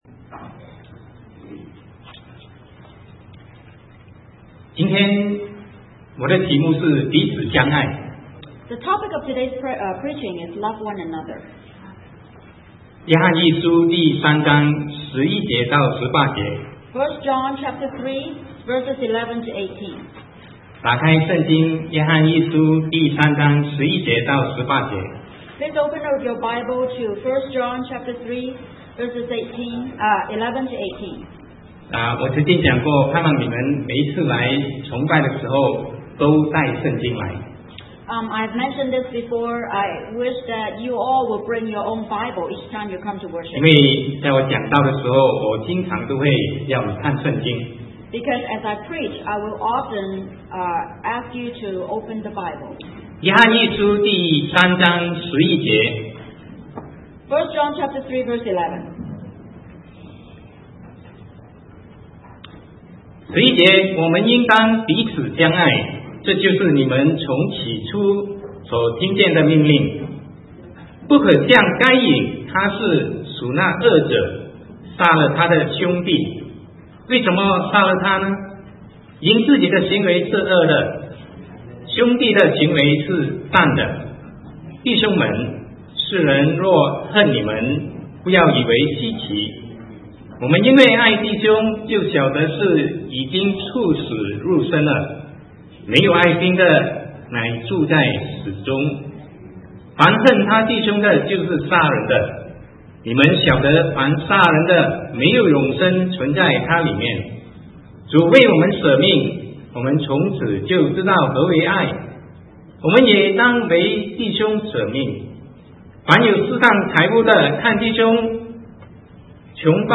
Sermon 2009-11-15 Love One Another